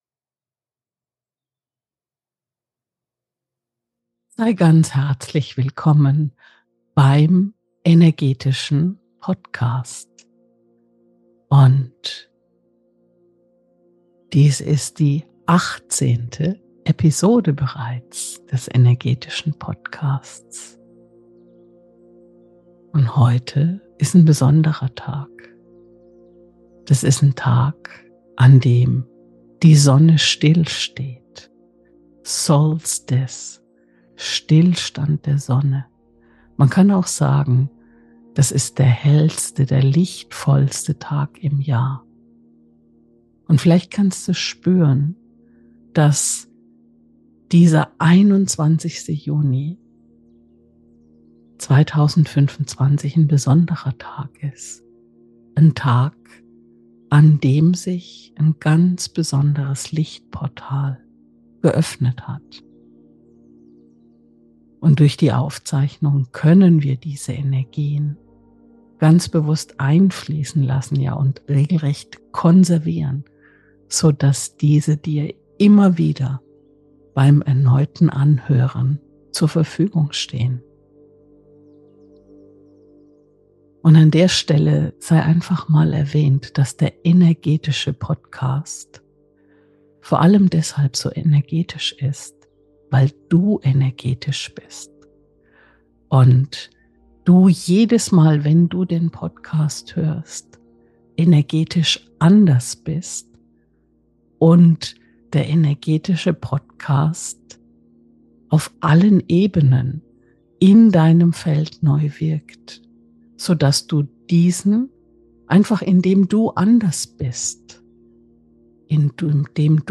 Diese besondere Episode wurde zur Sommer-Sonnenwende am 21. Juni 2025 aufgezeichnet – dem lichtvollsten Tag des Jahres.